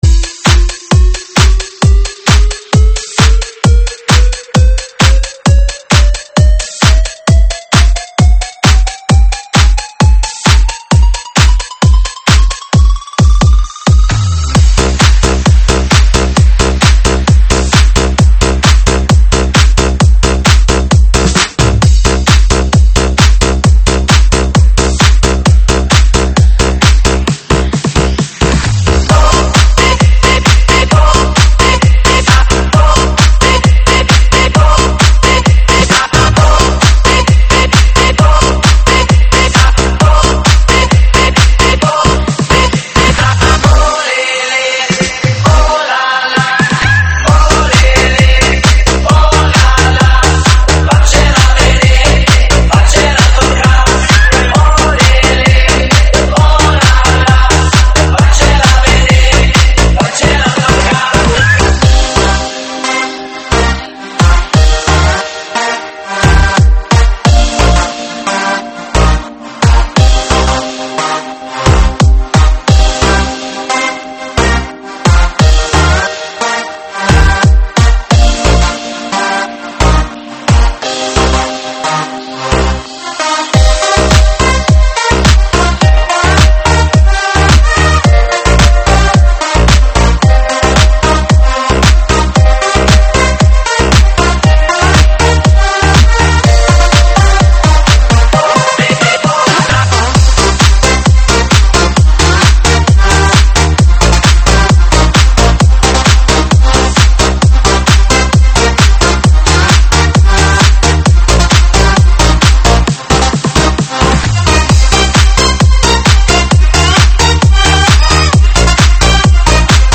舞曲类别：英文舞曲